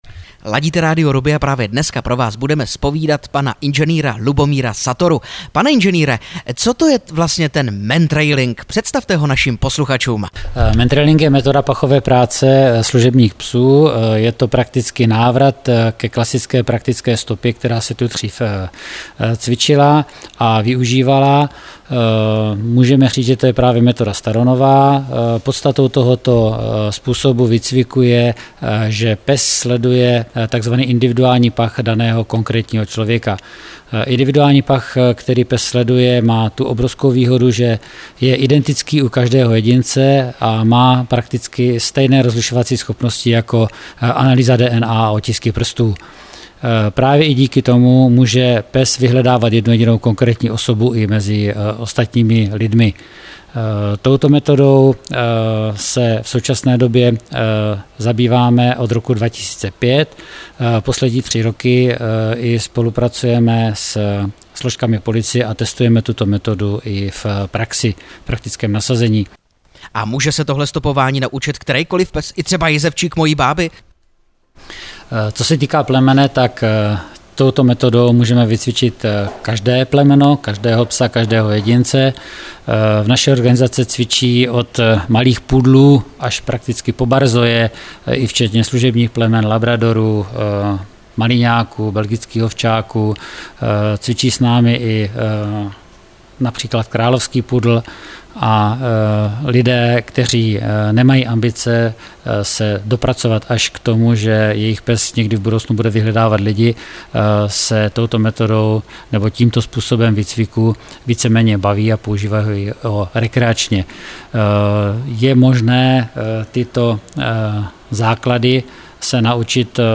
Reportáž na radiu RUBI